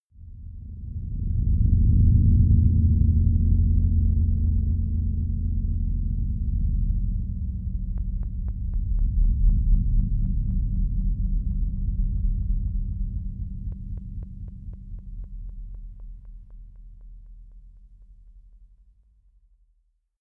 Royalty free sounds: Horror